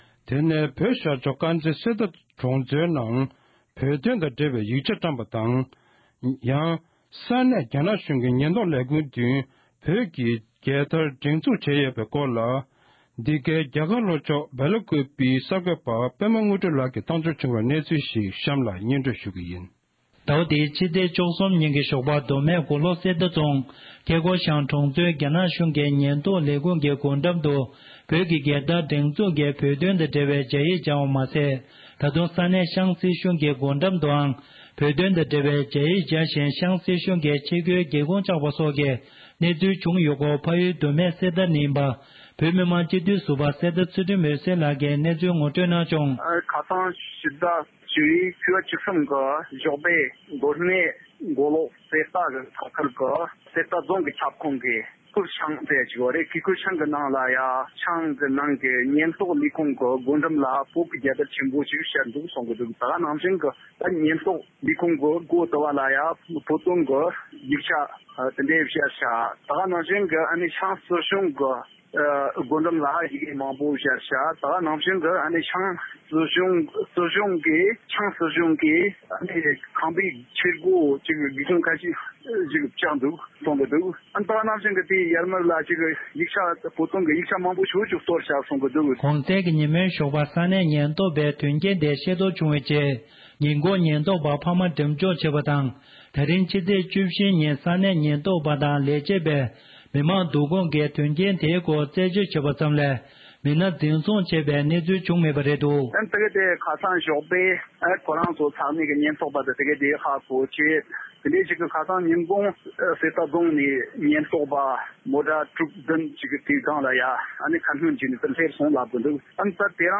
ཕ་ཡུལ་མདོ་སྨད་གསེར་རྟ་ནས་ཡིན་པའི་བོད་མི་དམངས་སྤྱི་འཐུས་ཟུར་པ་གསེར་རྟ་ཚུལ་ཁྲིམས་འོད་ཟེར་ལགས་ཀྱིས།